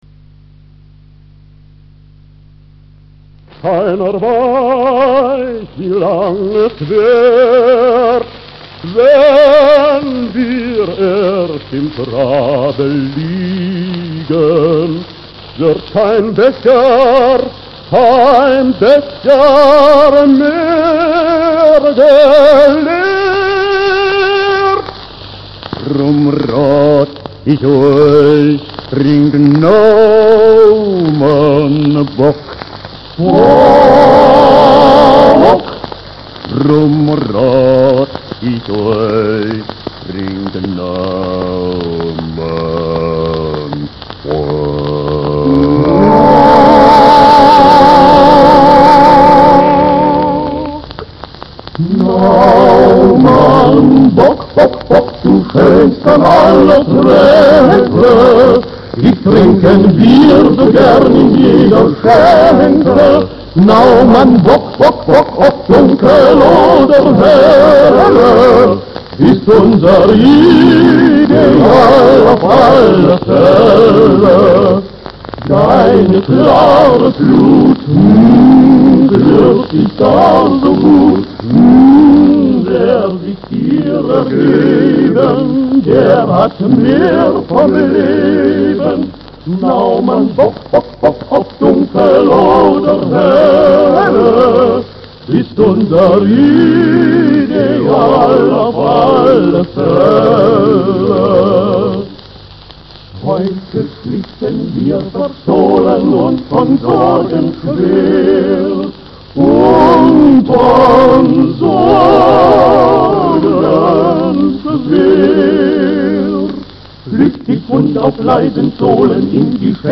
Naumann Bockbier - Historischer Werbespot 1 2,32 MB 1554 Sound abspielen!
naumann_bock_werbespot1.mp3